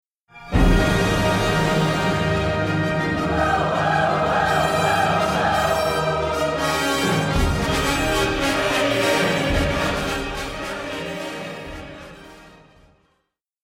The chorus (nymphs, sylvans, and fauns) sings “A-o a-o” at the climax of the scene, when the storm is at its full fury and Dido and Aeneas consummate their love.